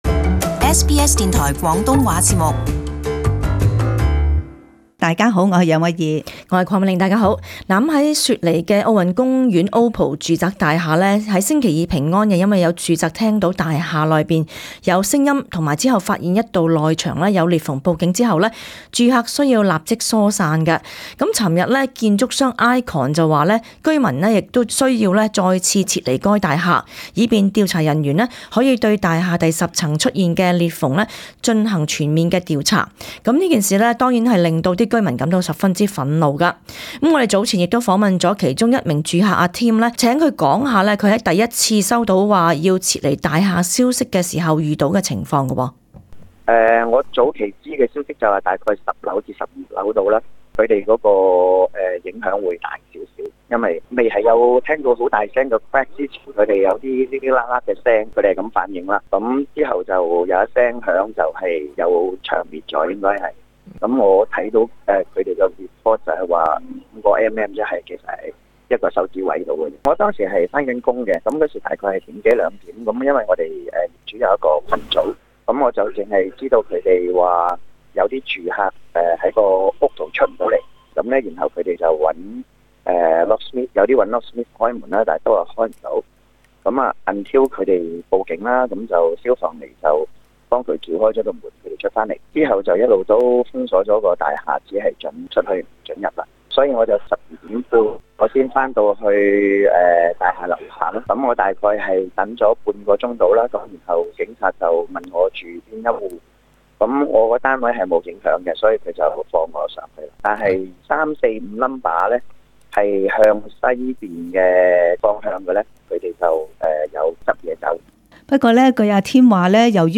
而居民對於需要第二次撤離大廈感到十分憤怒，認為建築商沒有告訴他們真相。 SBS廣東話節目 早前亦訪問了其中一名受影響的住客談談他在第一次收到消息指要撤離大廈消息時遇到的情況。